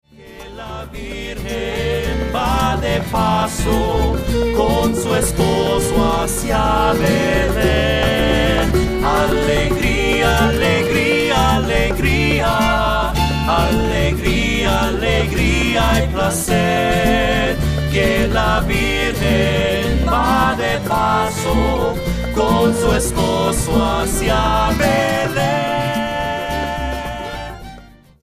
Guitar edition